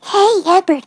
synthetic-wakewords
ovos-tts-plugin-deepponies_Fluttershy_en.wav